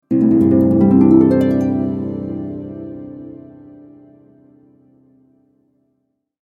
Calm-harp-transition-audio-logo.mp3